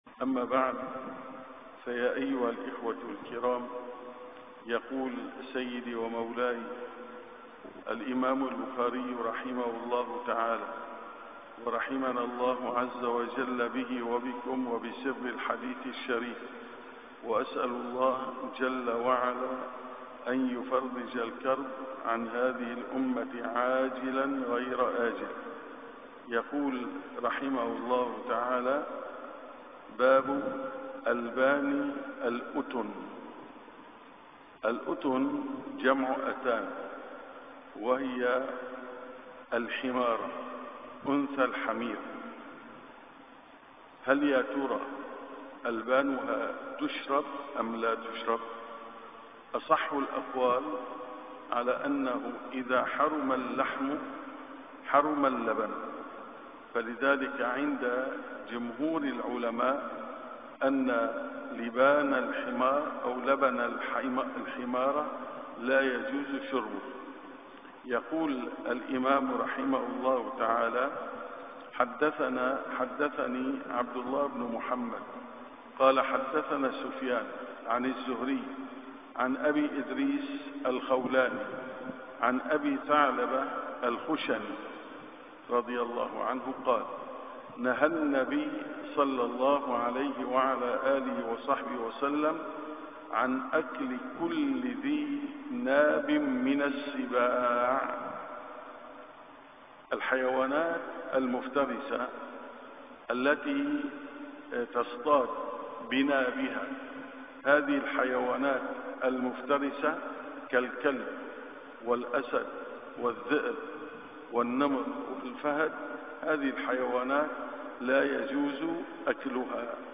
دروس في الحديث الشريف